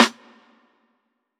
TC SNARE 11.wav